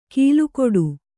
♪ kīlu koḍu